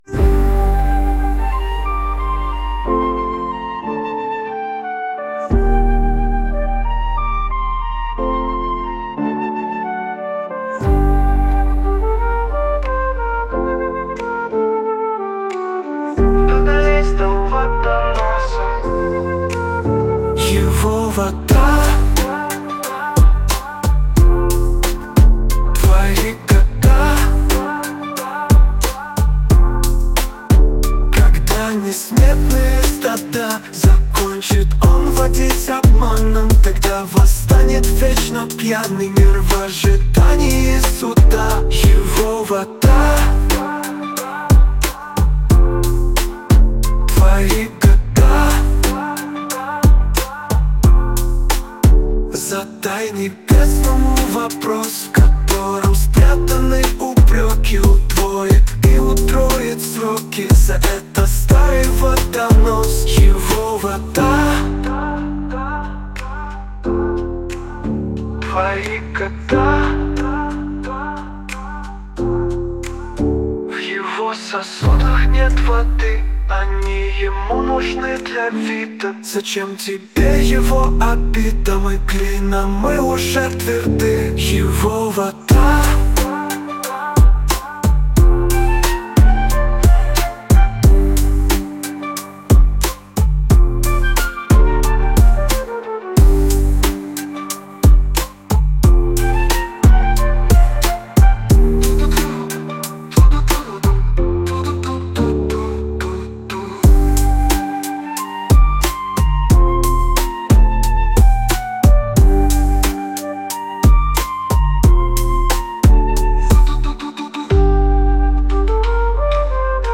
• Жанр: Фолк